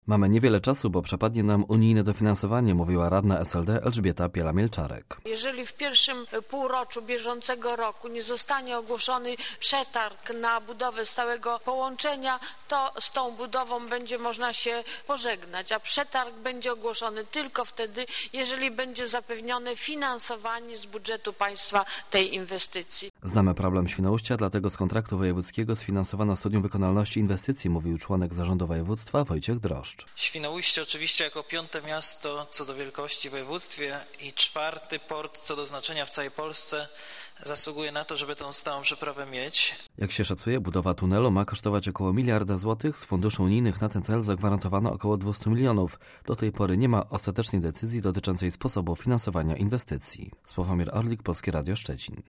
- Mamy niewiele czasu, bo przepadnie nam unijne dofinansowanie - mówiła radna SLD Elżbieta Piela-Mielczarek.
- Znamy problem Świnoujścia, dlatego z kontraktu wojewódzkiego sfinansowano studium wykonalności inwestycji - mówił członek zarządu województwa Wojciech Drożdż.